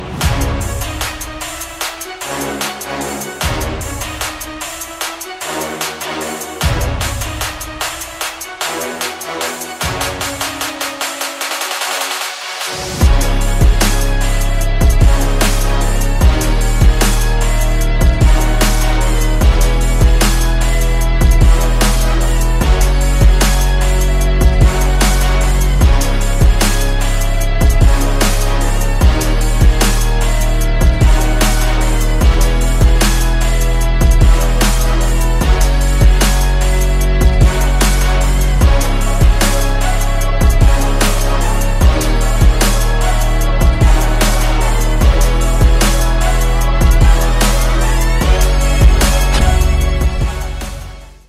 • Качество: 320, Stereo
громкие
брутальные
мощные басы
без слов
Trap
эпичные